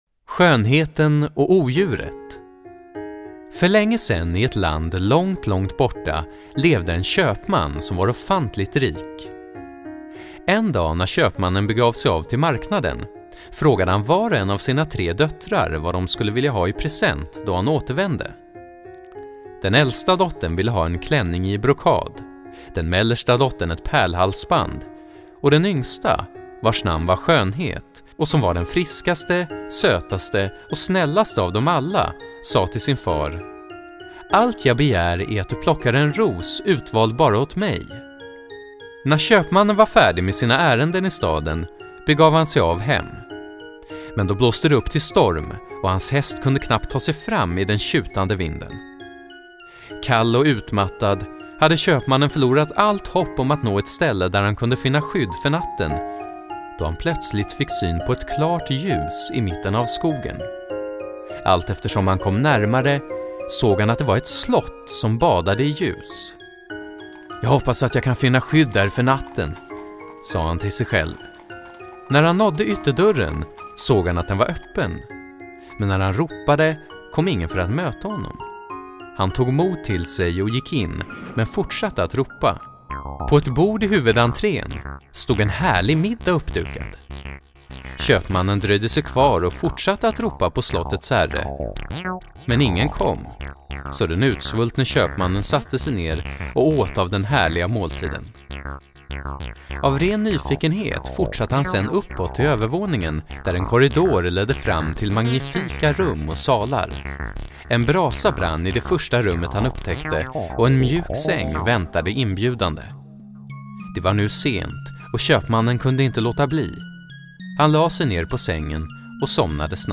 Ljudbok 3